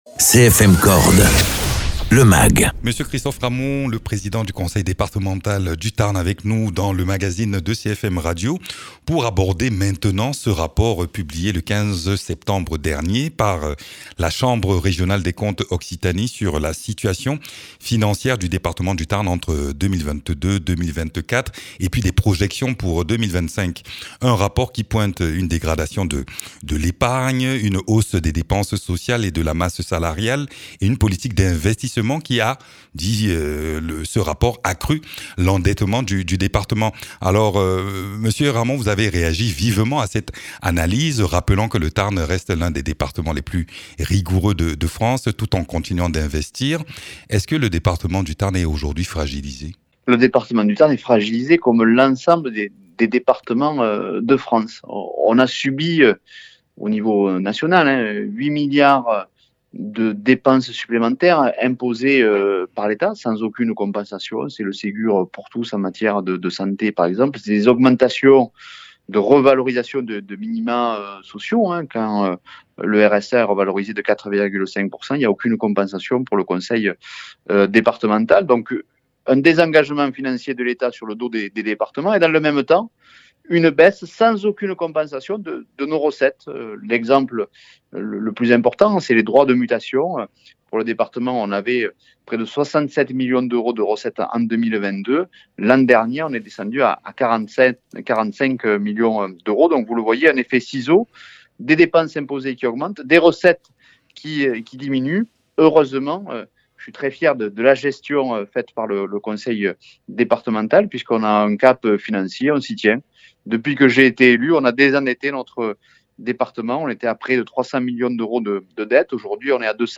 Interviews
Invité(s) : M. Christophe Ramond, Président du Département du Tarn.